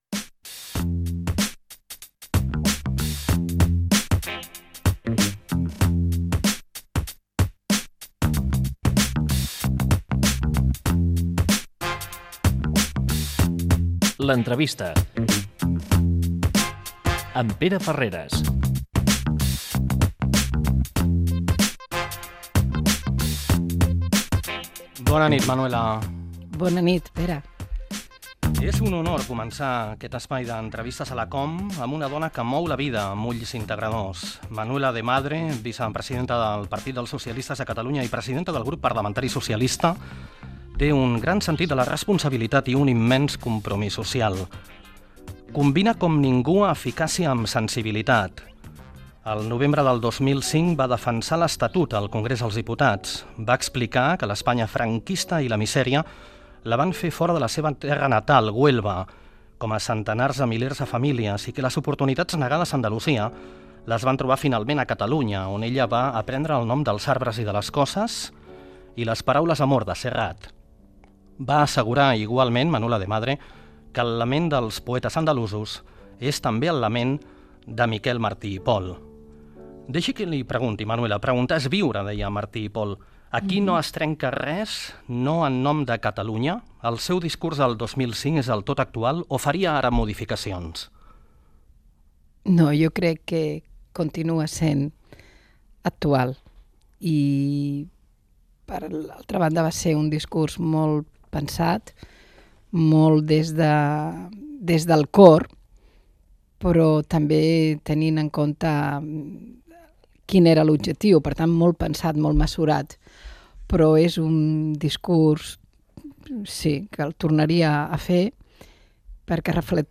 Careta del programa, fragment d'una entrevista a l'alcaldessa de Santa Coloma de Gramenet Manuela de Madre